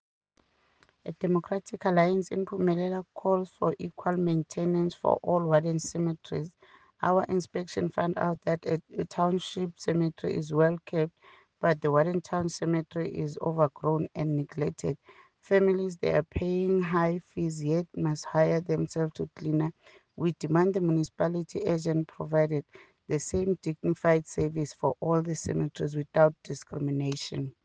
English soundbite by Cllr Ntombi Mokoena and